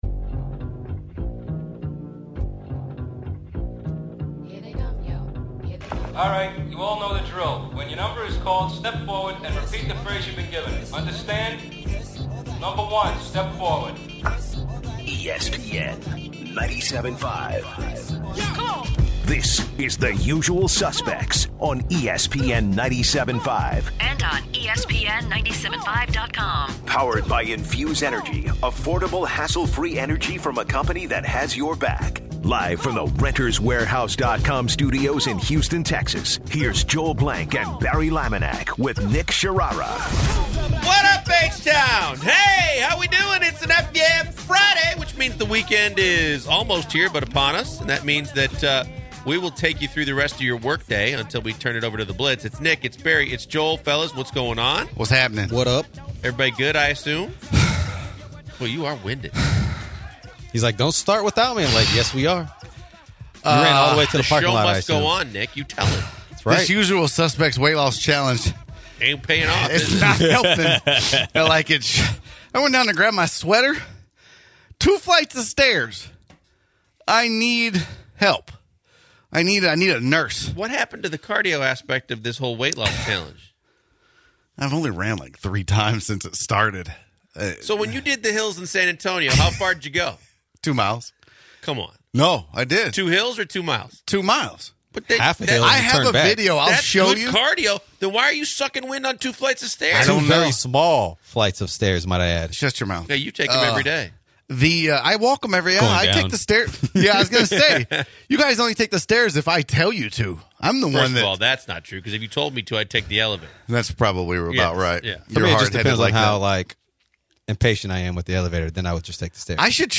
joins the guys in the studio